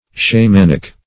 Shamanic \Sha*man"ic\, a.